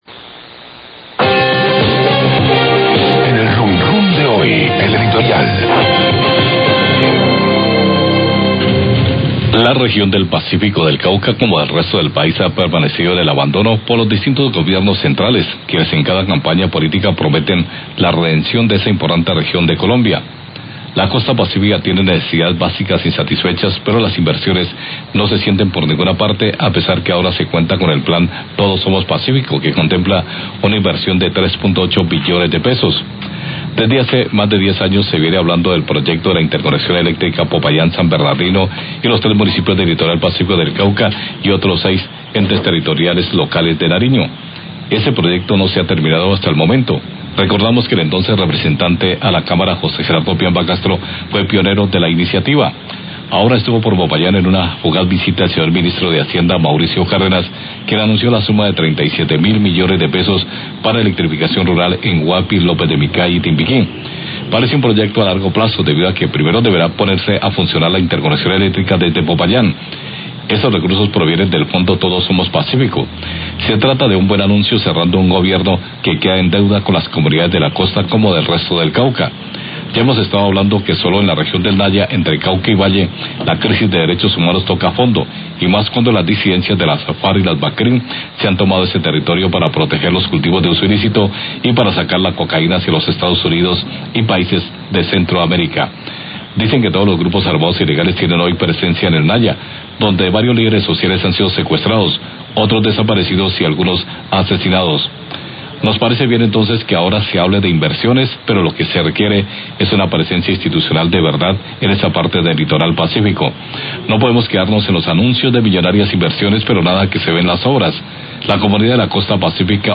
Radio
Columna de opinión donde se asegura que la región del Pacífico ha permanecido en el abandono, las inversiones no se sienten a pesar de que ahora se cuenta con el Plan Todos Somo Pazcífico.